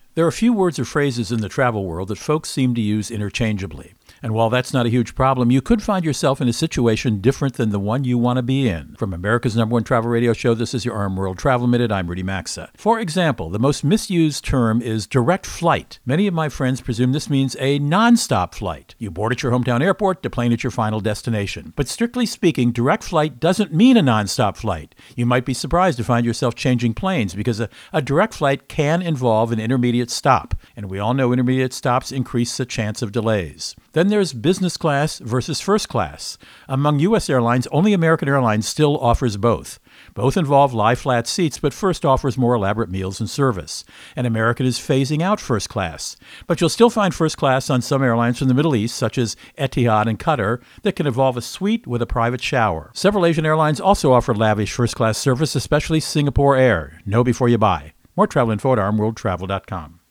Co-Host Rudy Maxa | Airline Terms that Confuse
RMWTM-2549-Confusing-Airline-Terms-Rudy.mp3